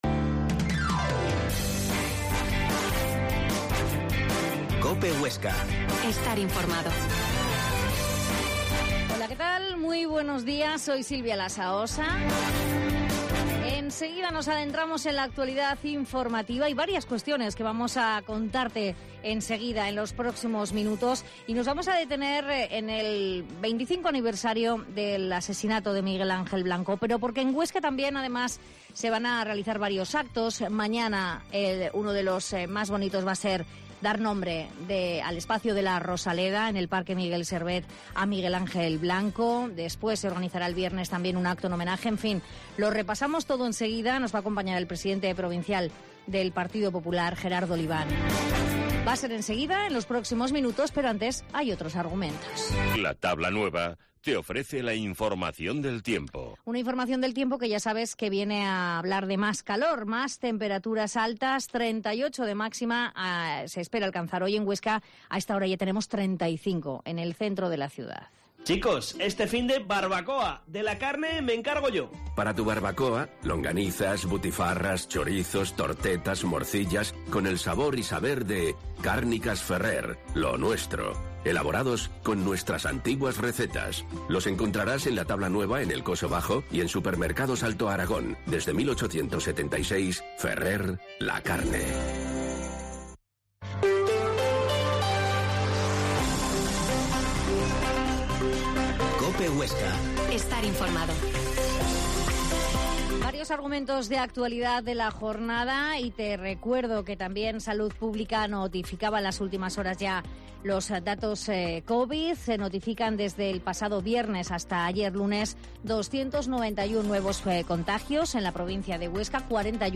Herrera en COPE Huesca 12.50h Entrevista a Gerardo Oliván, Pdte del PP Huesca